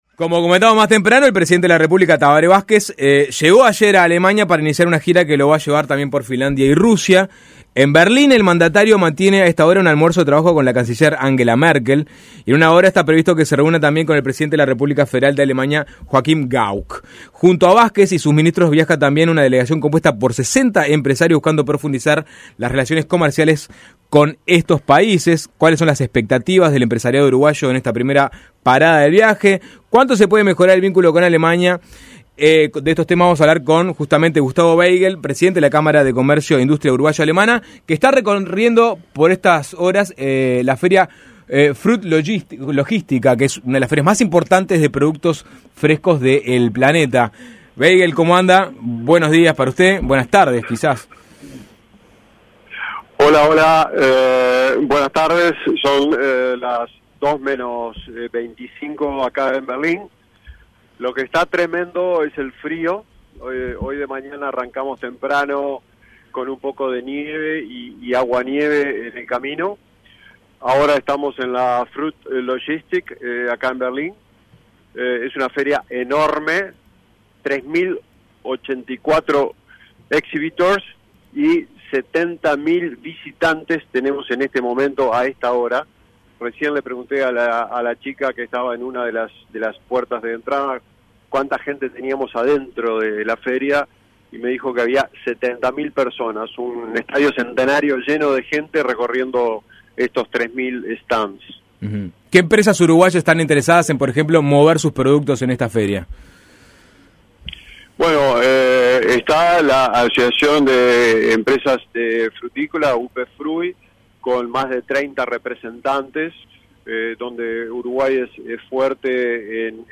En entrevista desde Berlín con Suena Tremendo